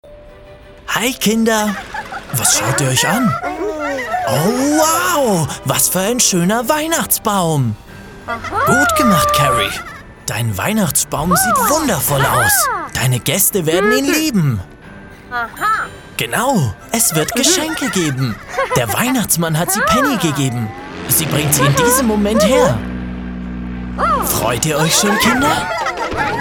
Teenager, Young Adult, Adult
german | natural
ANIMATION 🎬
Kinder_Anime_-_Super_Truck.mp3